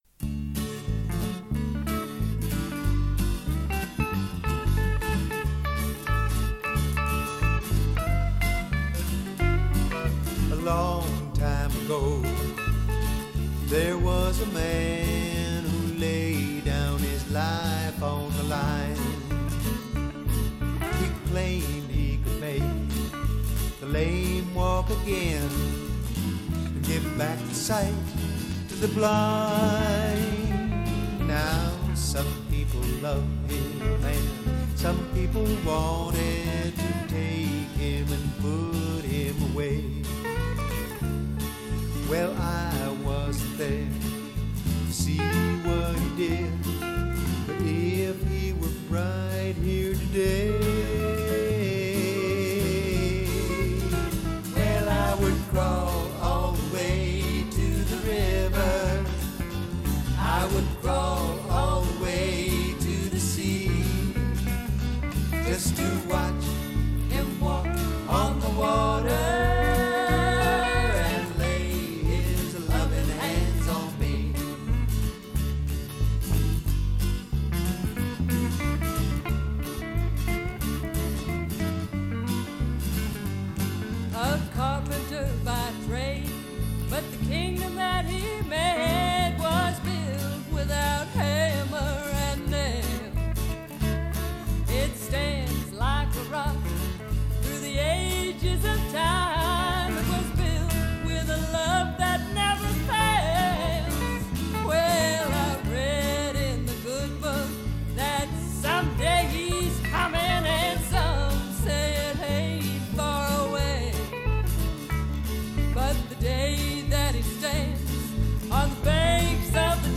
1. Devotional Songs
Major (Shankarabharanam / Bilawal)
8 Beat / Keherwa / Adi
Fast
4 Pancham / F
1 Pancham / C
Lowest Note: d2 / A (lower octave)
Highest Note: P / G